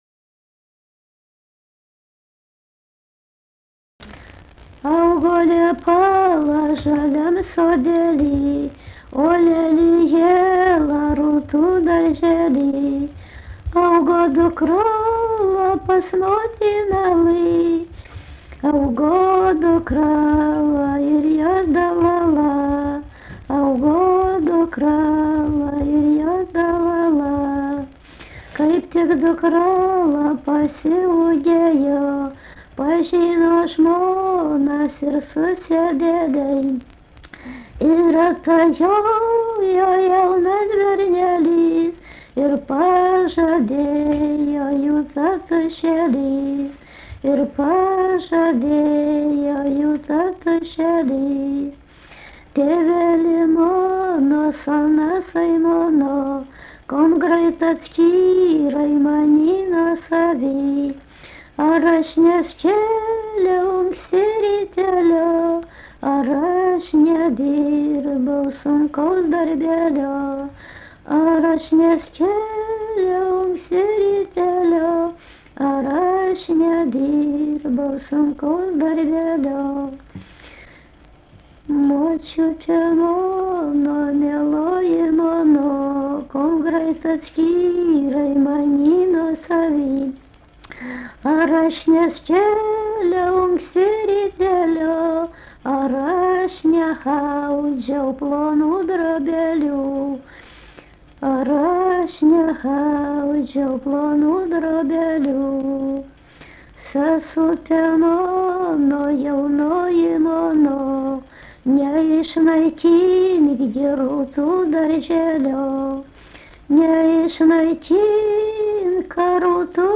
daina
Rėkučiai
vokalinis